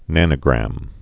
(nănə-grăm)